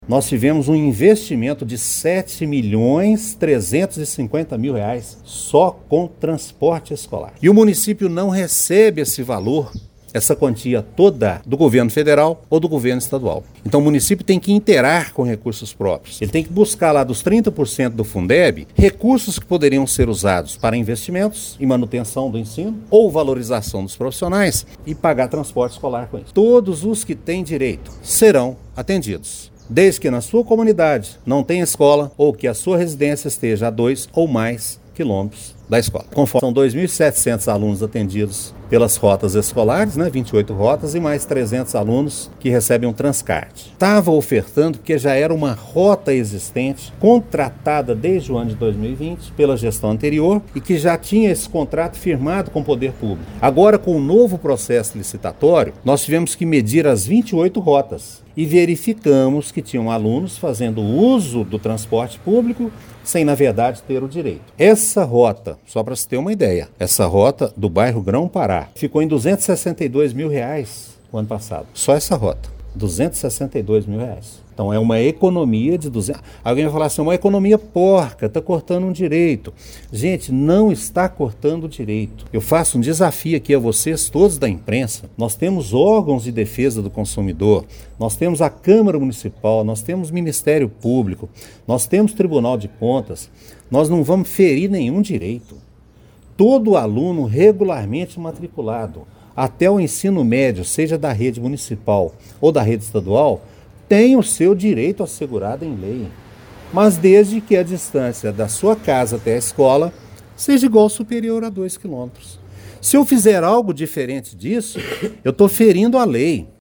Durante coletiva de imprensa, o secretário de Educação, Marcos Aurélio dos Santos, destacou que o transporte escolar é um direito garantido pela Constituição Federal e por legislações específicas que regulam o setor.